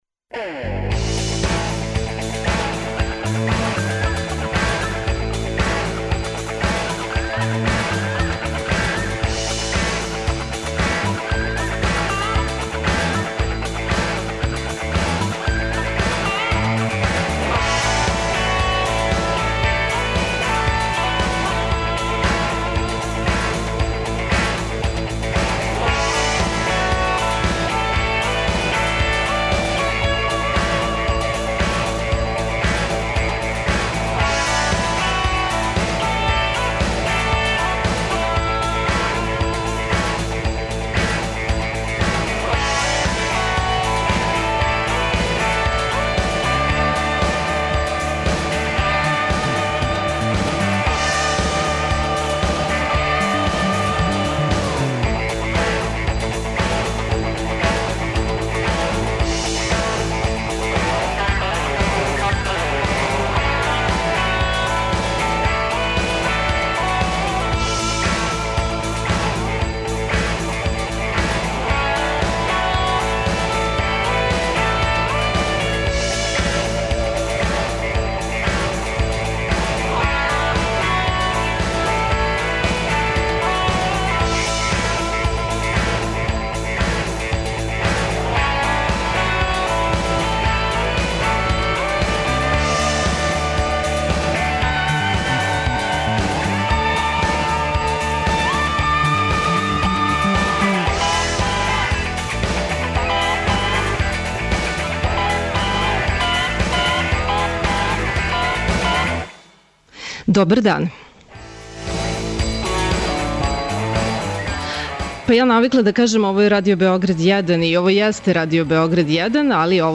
Горан Шепа – познатији као Гале, а најпознатији по свом специфичном вокалу, вођа некада веома популарног бенда Кербер биће гост специјалног издања емисије коју реализујемо из Ниша.